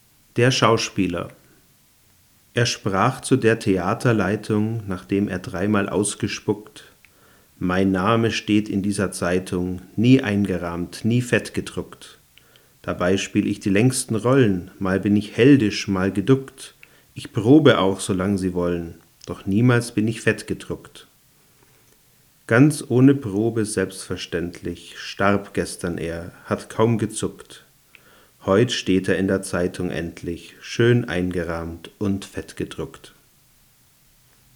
Um das gefürchtete Rauschen zu vergleichen, habe ich ein dynamisches Mikrofon benutzt und aus 60 cm Abstand besprochen.
Mikrofon direkt am Mic-Eingang des Recorders Zoom H5
zoom_h5.mp3